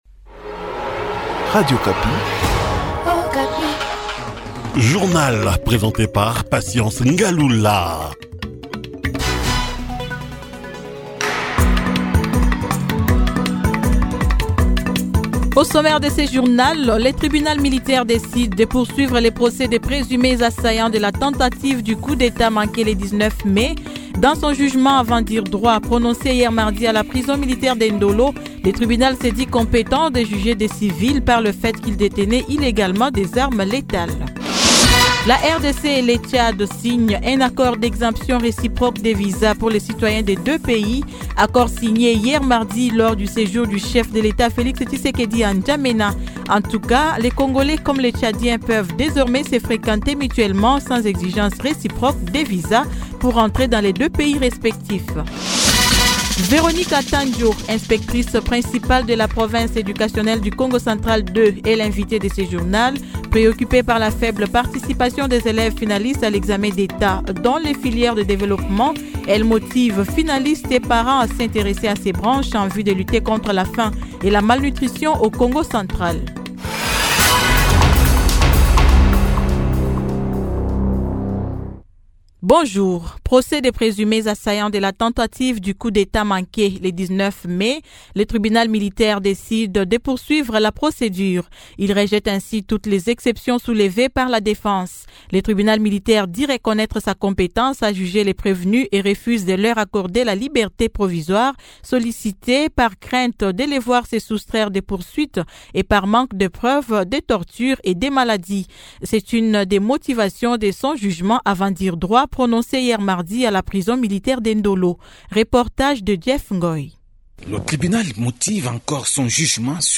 Journal matin 06H-07H